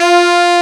A SAX   6.wav